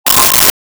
Sci Fi Beep 05
Sci Fi Beep 05.wav